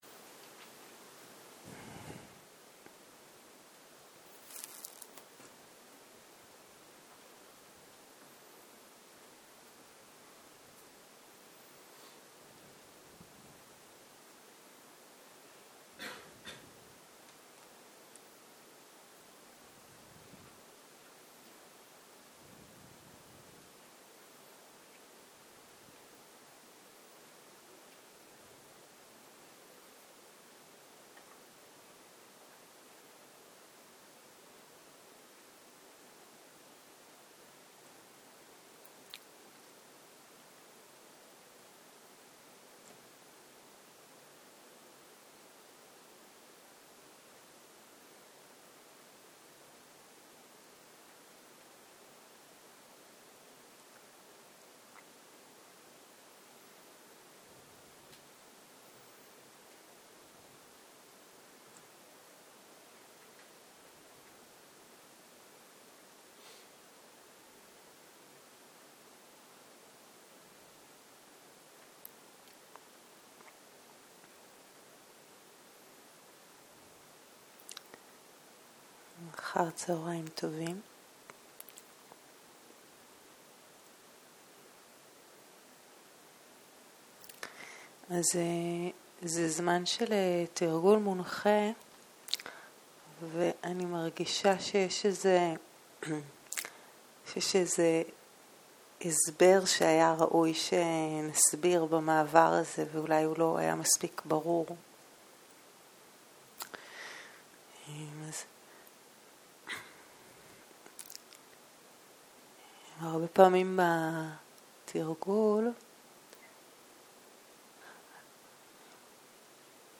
צהריים - מדיטציה מונחית - אופקהה
סוג ההקלטה: מדיטציה מונחית